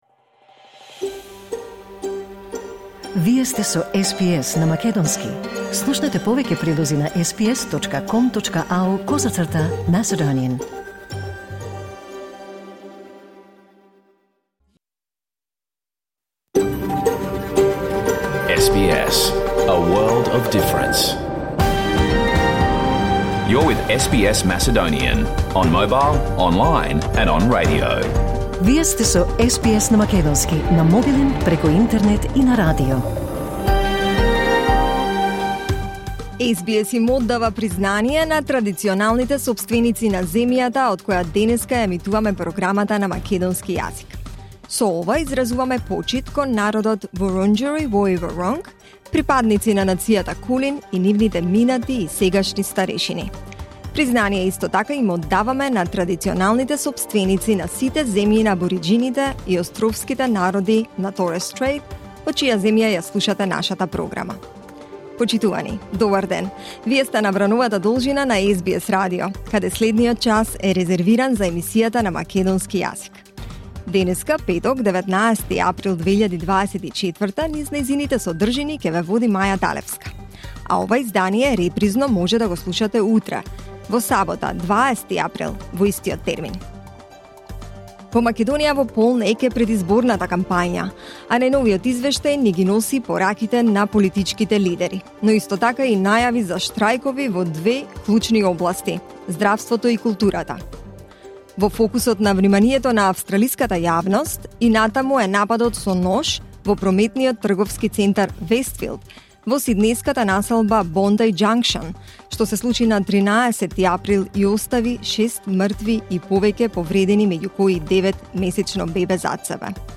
SBS Macedonian Program Live on Air 19 April 2024